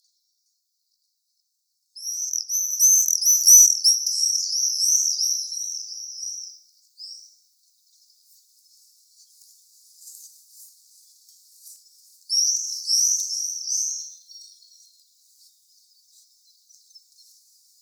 Apus apus - Swift - Rondone
- COMMENT: The first long call series is a typical vocalisation produced by a flock.